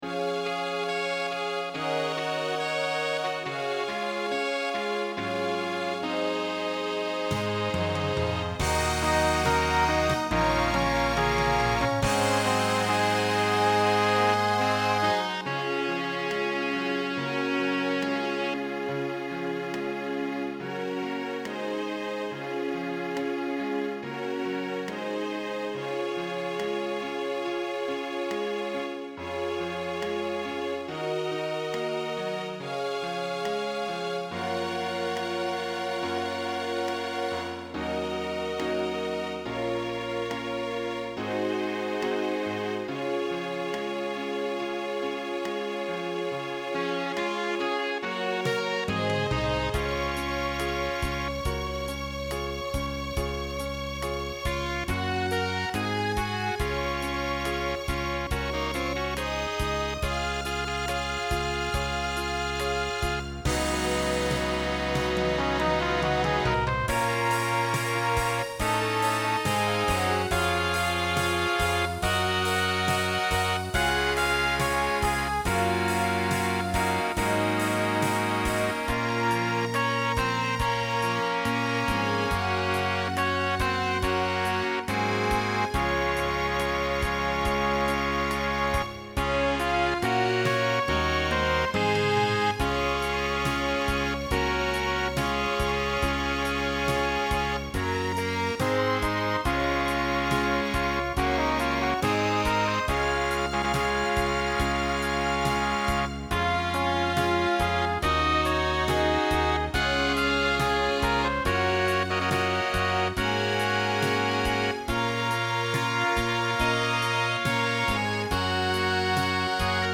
Voicing SATB Instrumental combo Genre Pop/Dance
Ballad